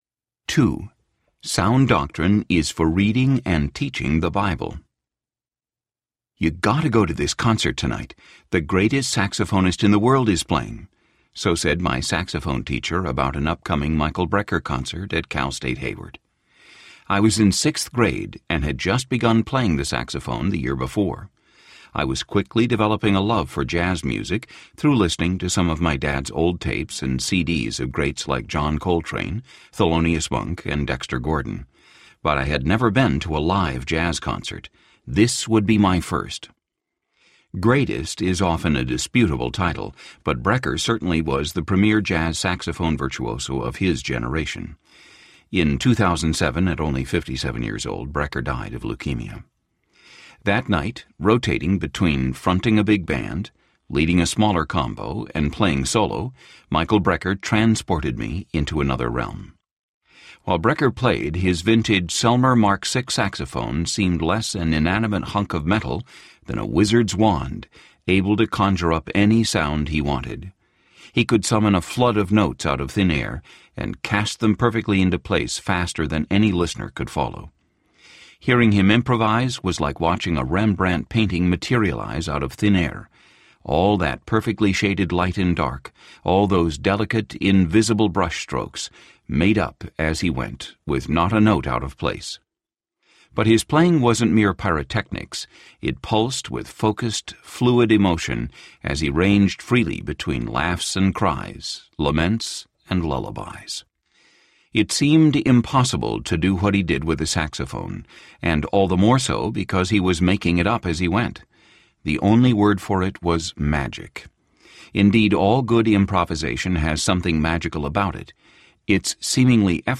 Sound Doctrine (9Marks Series) Audiobook
Narrator
2.52 Hrs. – Unabridged